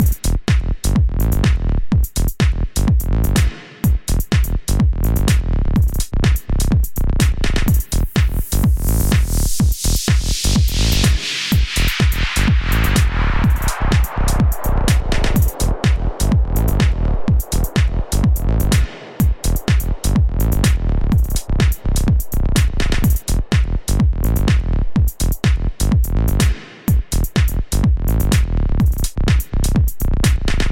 Listen on how both the Base and White noise tracks are compressed.
electro-loop-multiple-comp.mp3